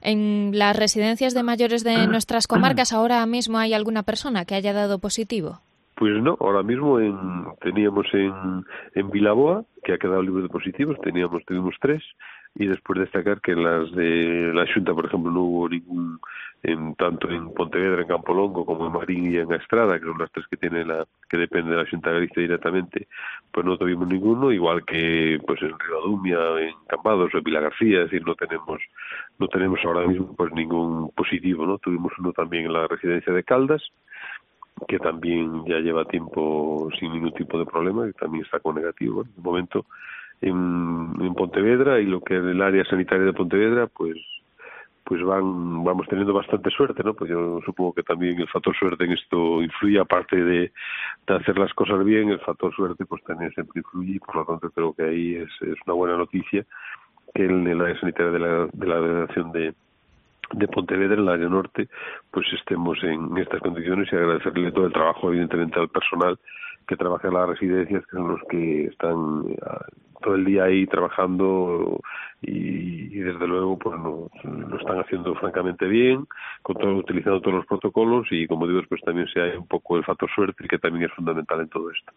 En declaraciones a esta emisora, el delegado territorial de la Xunta de Galicia en Pontevedra, José Manuel Cores Tourís, ha querido agradecer "todo el trabajo al personal de las residencias. Lo están haciendo francamente bien".